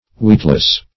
Weetless \Weet"less\, a. Unknowing; also, unknown; unmeaning.